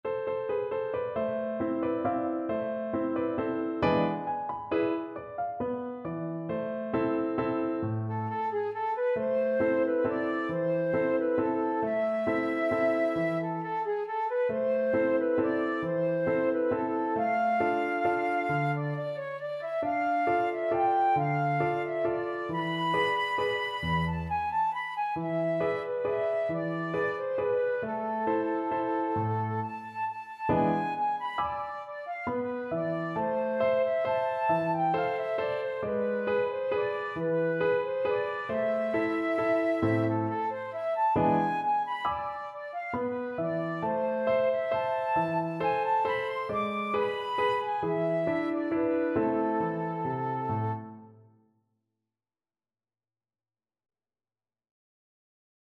Slow one in a bar .=c.45
3/4 (View more 3/4 Music)